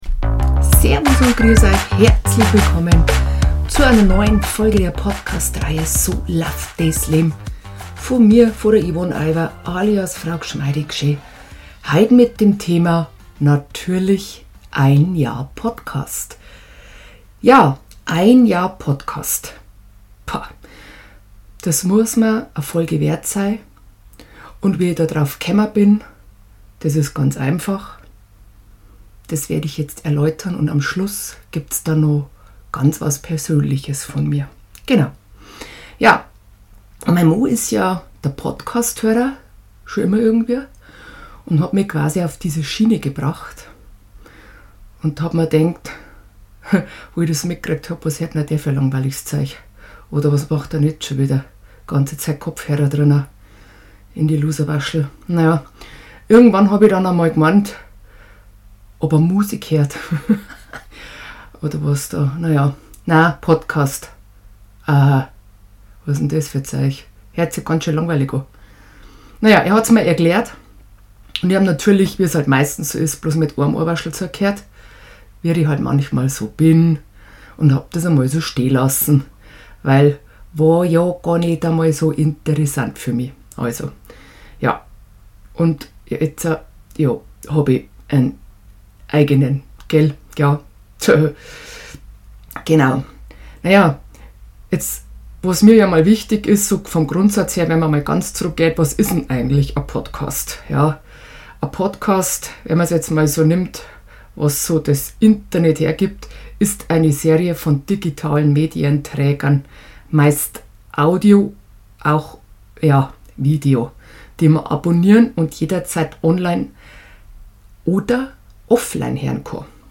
Mein kleines Podcast Mini Studio. Danke an alle Fans und Hörer und Abonnementen.